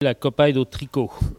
Localisation Saint-Jean-de-Monts
Patois
Catégorie Locution